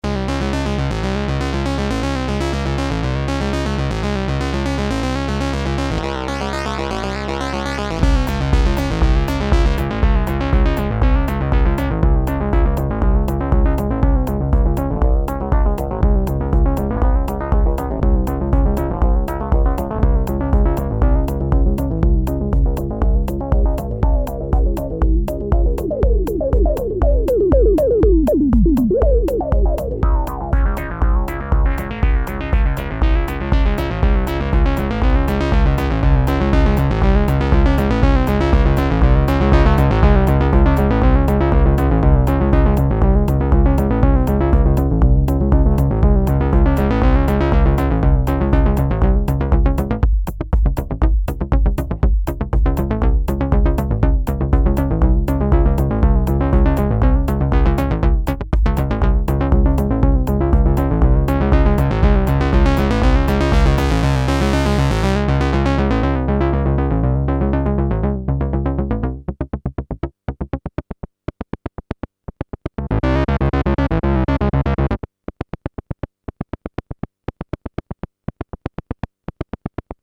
Arp Odyssey
synched sounds